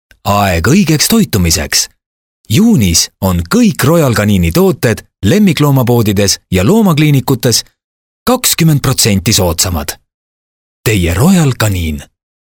Voice Over Artistes- ESTONIAN